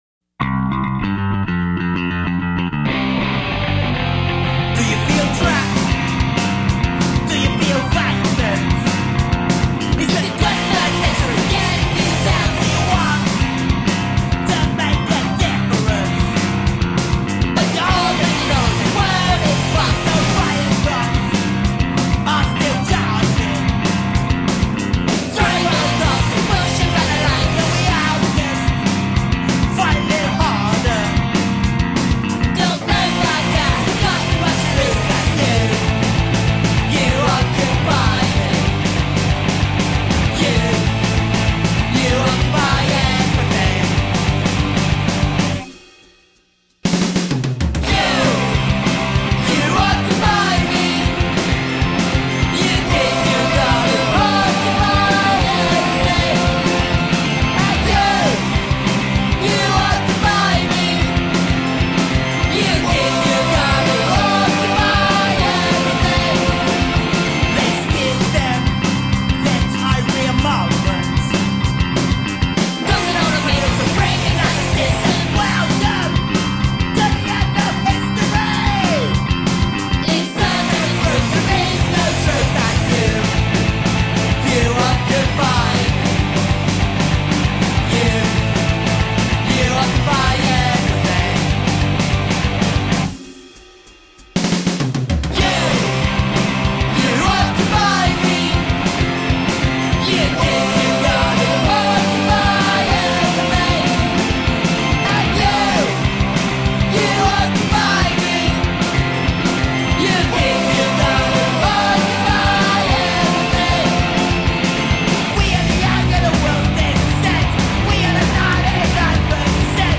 Punk rock to blow your head off.
Tagged as: Hard Rock, Punk, Instrumental, Intense Metal